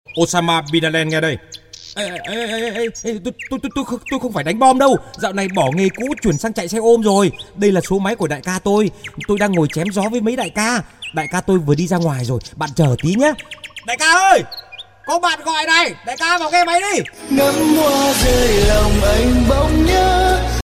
Thể loại nhạc chuông: Nhạc hài hước